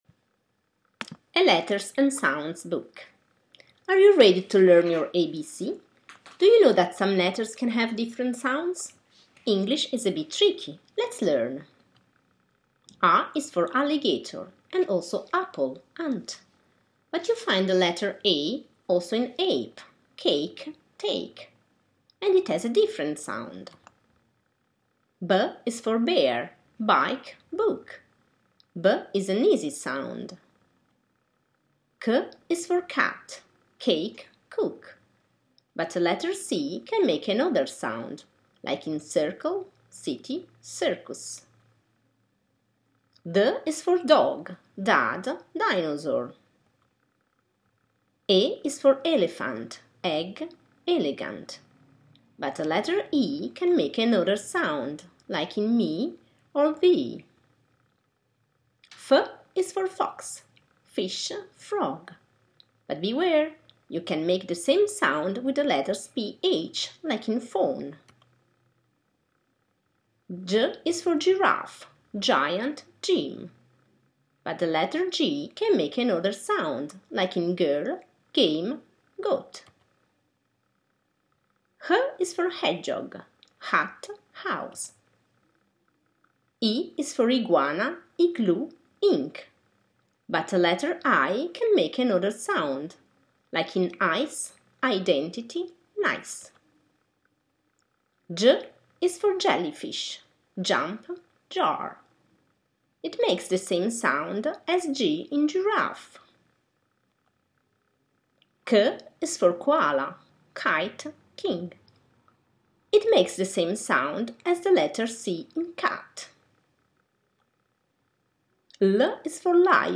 Clicca sulla freccia per ascoltare l’audio con la corretta pronuncia:
A-letters-and-sounds-book.m4a